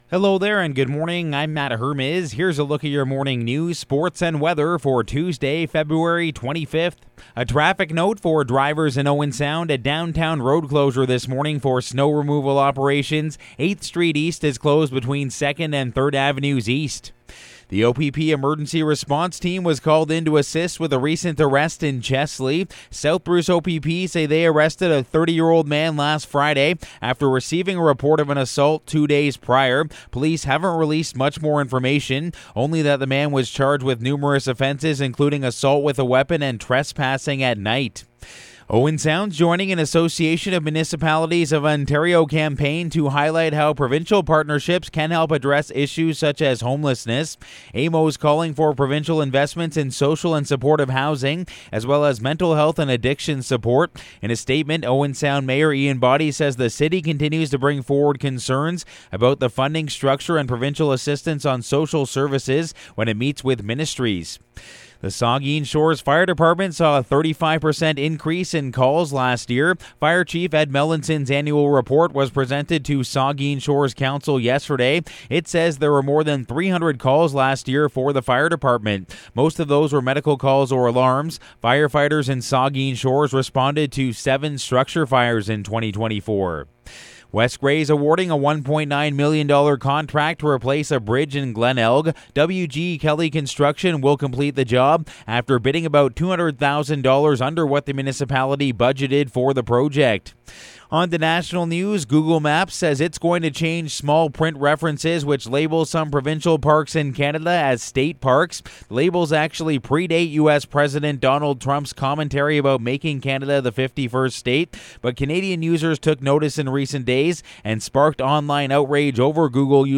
Morning News – Tuesday, February 25